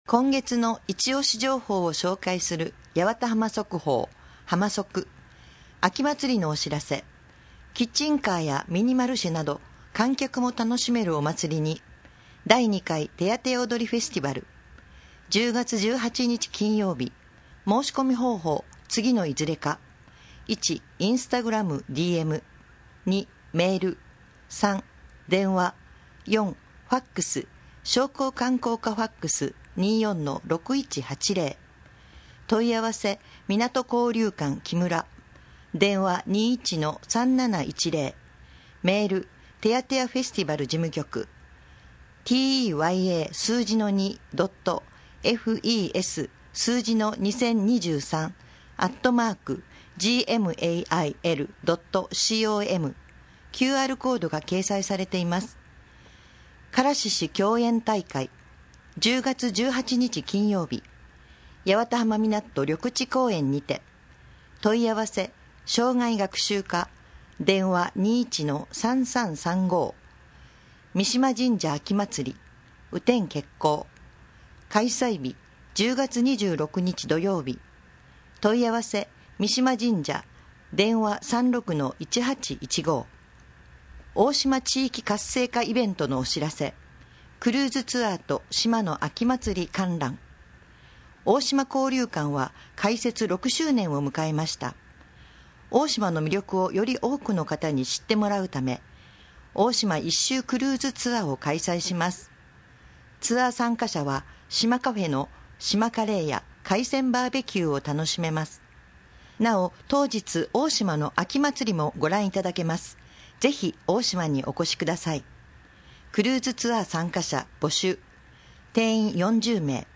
なお「声の広報」は、朗読ボランティアどんぐりの協力によって作成しています。